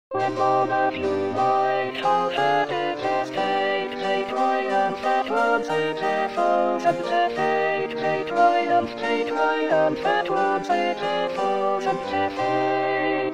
Alto
05-when-monarchs_Alto.mp3